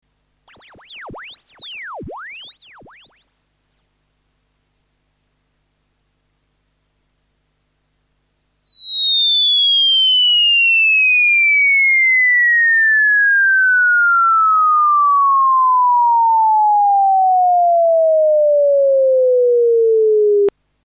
スウィープ信号 -9.0dB (20kHz-400Hz; Sine; Stereo)
ゲインM |